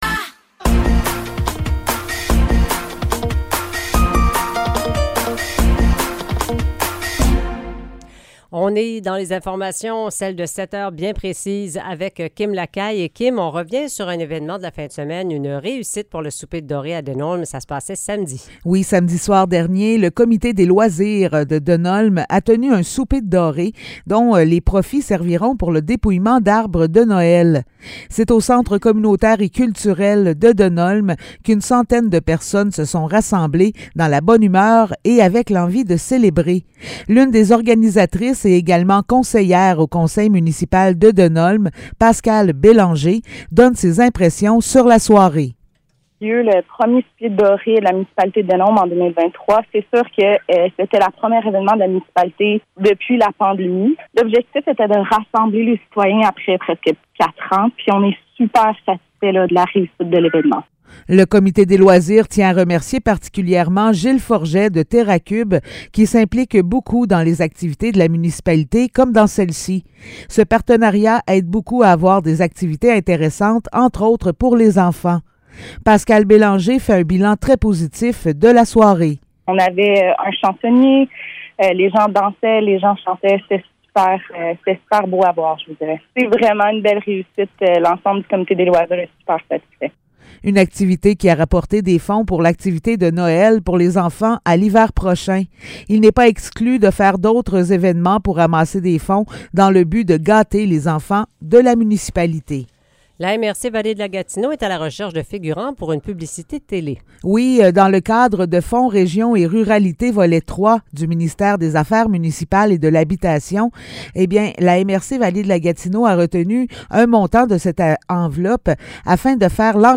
Nouvelles locales - 31 juillet 2023 - 7 h